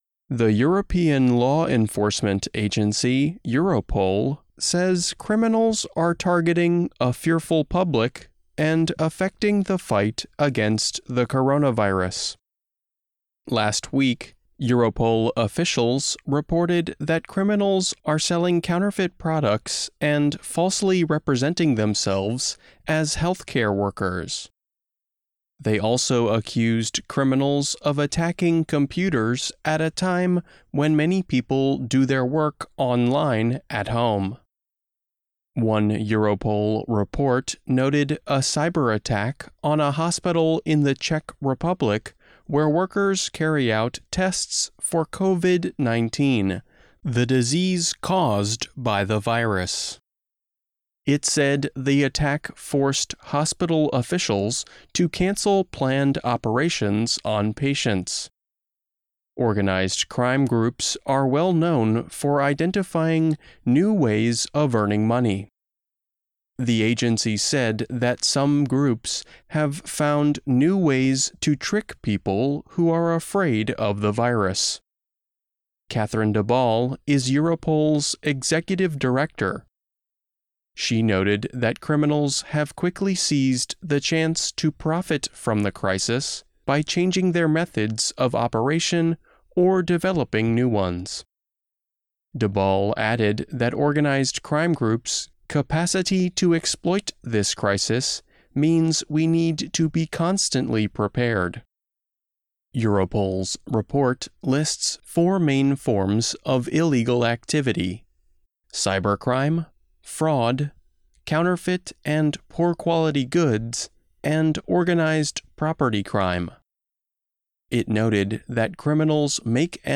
欧洲刑警组织警告有组织犯罪集团利用病毒危机攻击恐慌民众,慢速英语, 新闻杂志